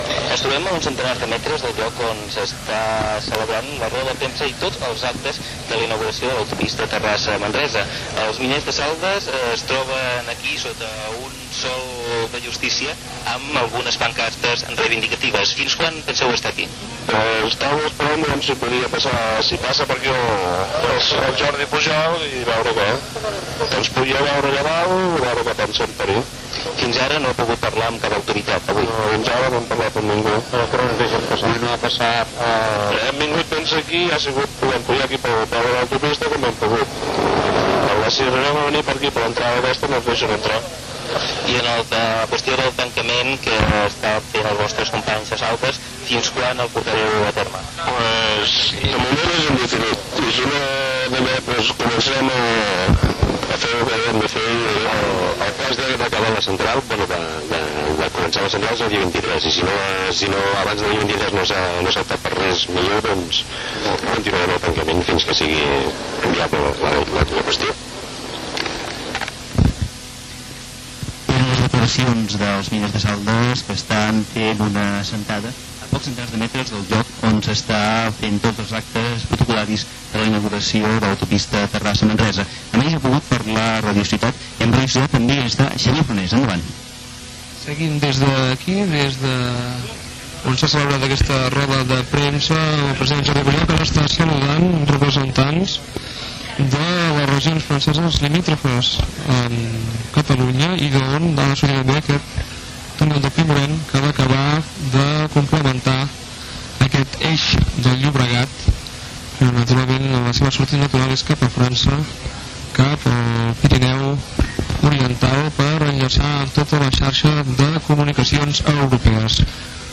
Protesta dels miners de Saldes, entrevista amb el director general de Carreteres, Jaume Amat, comiat del programa. Indicatiu de l'emissora.
Informatiu